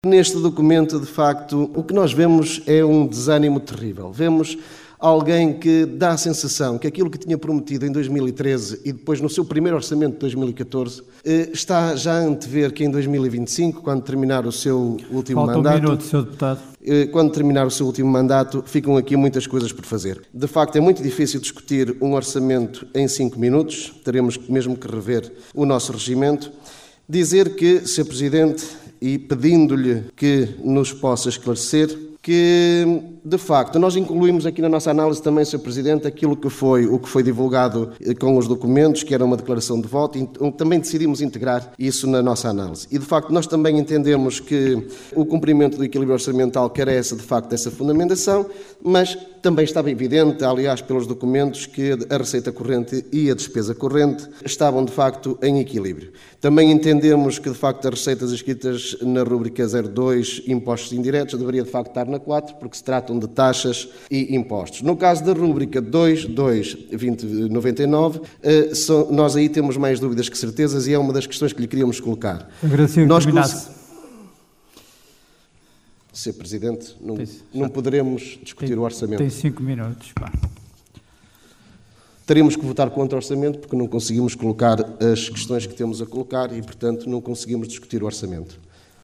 Realizada no cineteatro dos bombeiros voluntários de Vila Praia de Âncora, decorreu em tom de crispação de início ao fim.